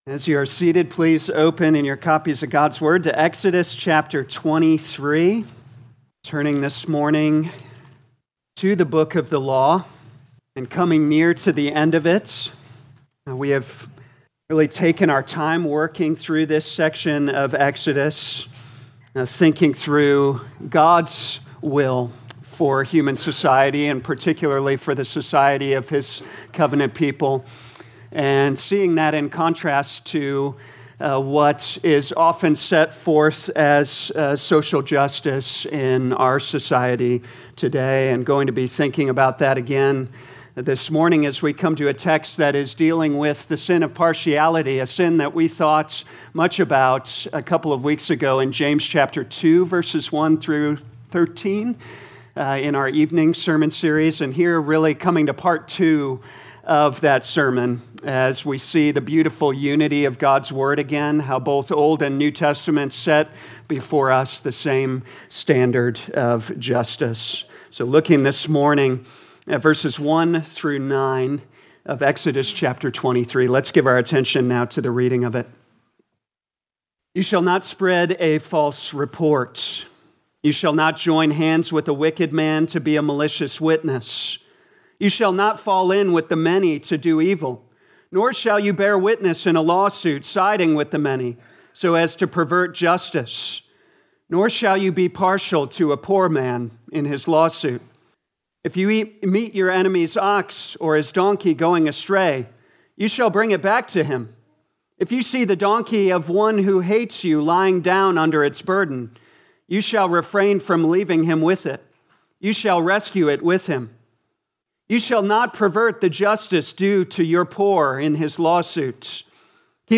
Audio Notes Bulletin All sermons are copyright by this church or the speaker indicated.